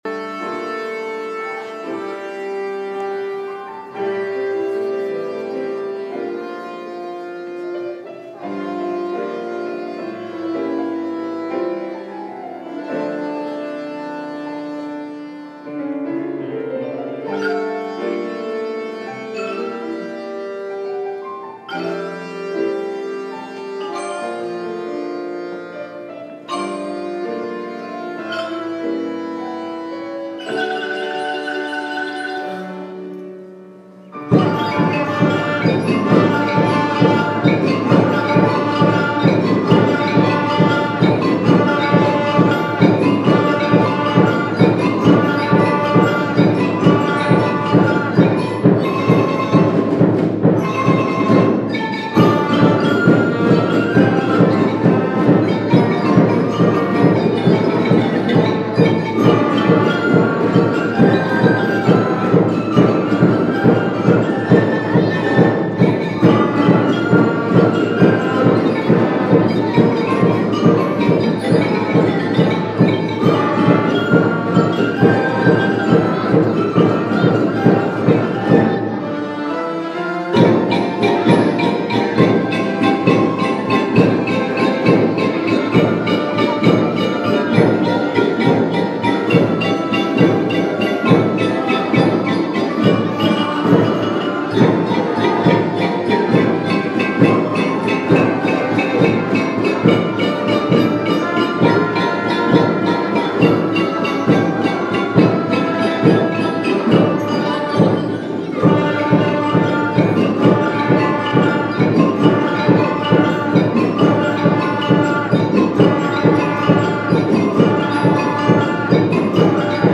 六年生を送る会
第一部は３，４校時を使って体育館で、演奏や、合唱、呼びかけ等を通してお互いに感謝の気持ちを伝え合いました。
アンコールも起こって二回目の演奏では全校で手拍子をして一体感が生まれました。